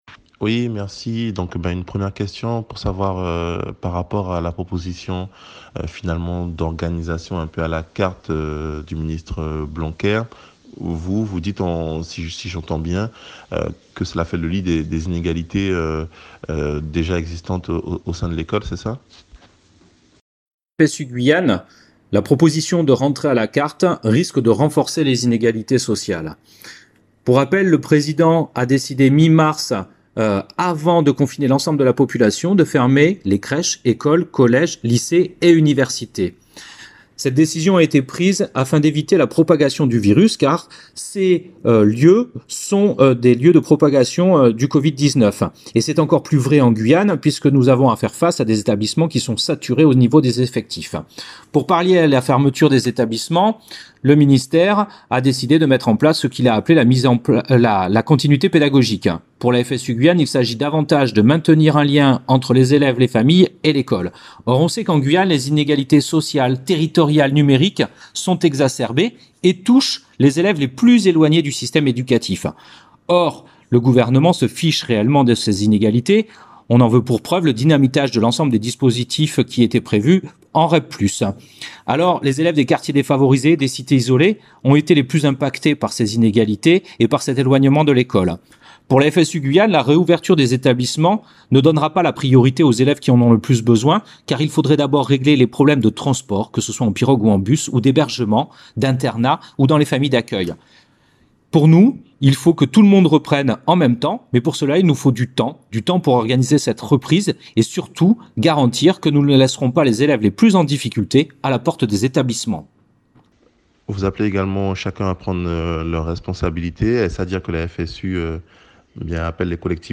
Vous trouverez les réponses de la FSU Guyane en écoutant l’interview [ ici ]